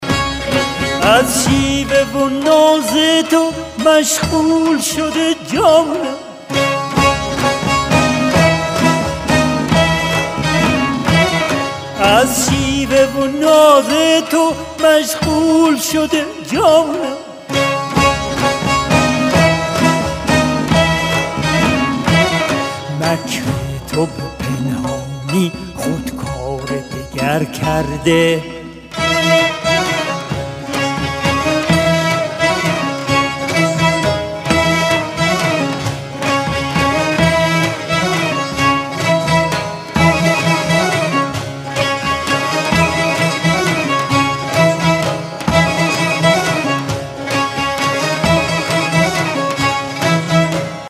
رینگتون موبایل باکلام (سنتی) احساسی